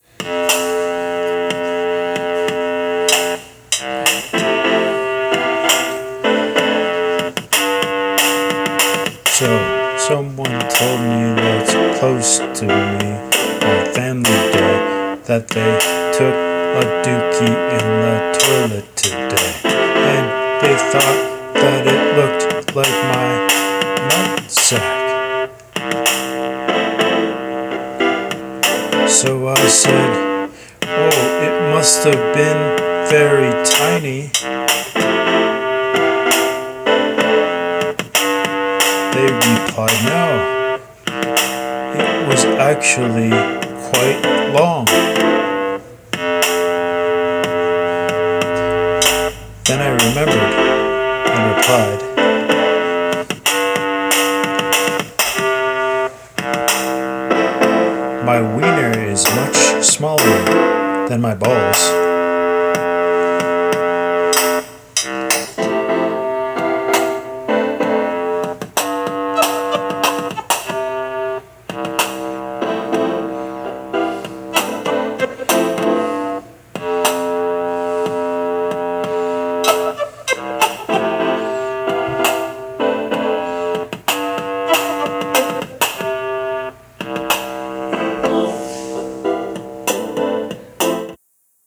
Freestyle over offensive artiphon orba